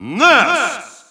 Announcer pronouncing Ness's name in Russian.
Ness_Russian_Announcer_SSBU.wav